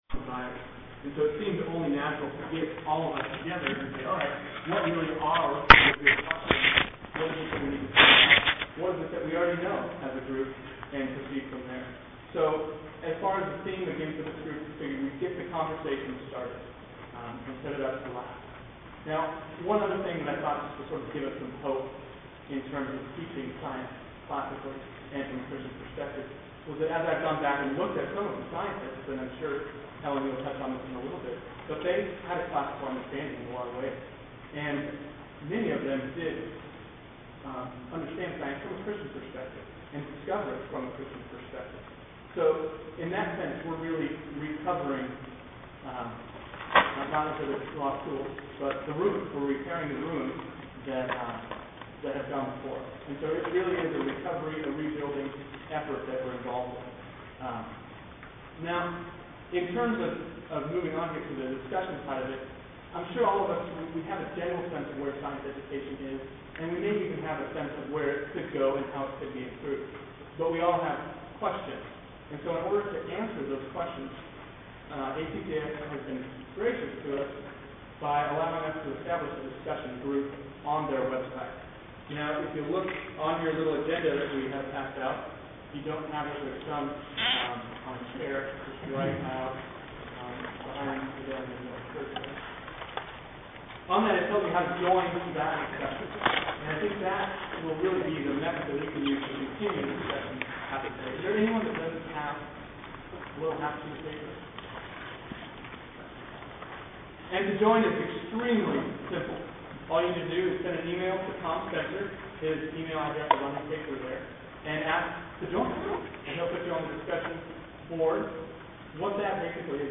2009 Workshop Talk | 0:51:15 | All Grade Levels, Science